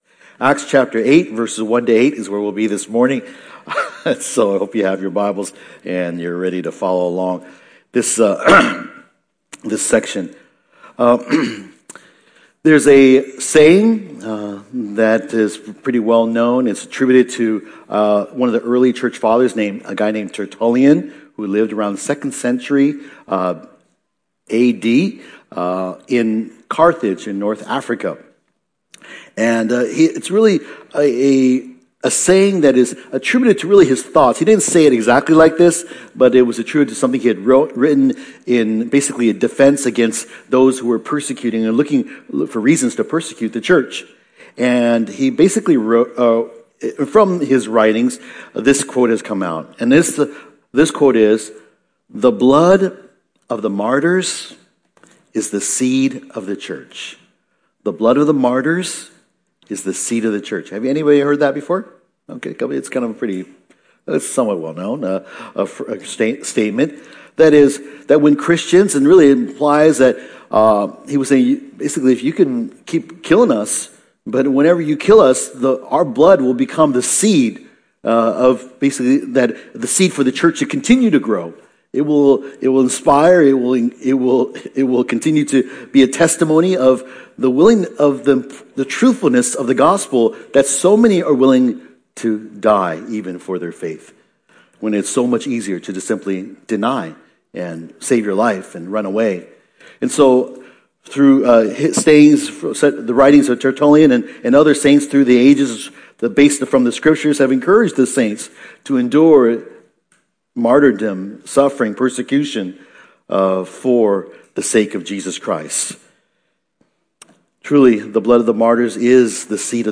San Francisco Bible Church - Sunday Sermons